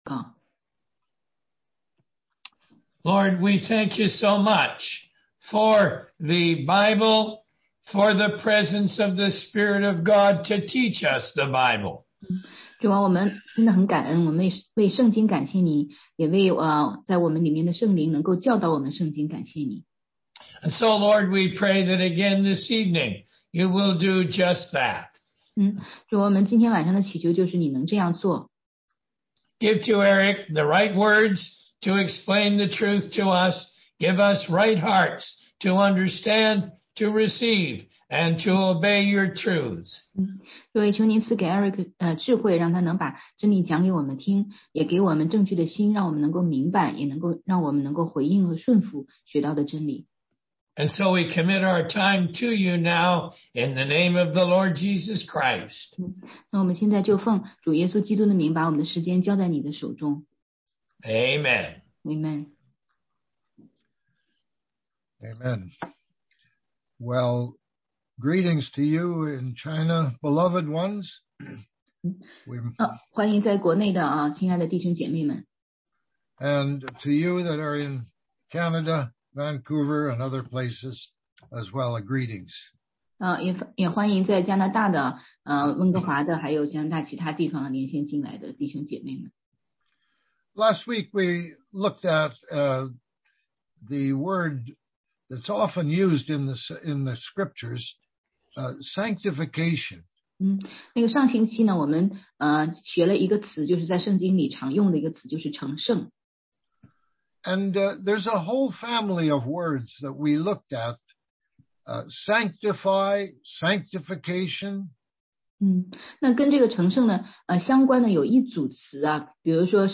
16街讲道录音 - 赎罪(Atonement)、挽回祭(Propitiation)与和好（reconciliation)的含义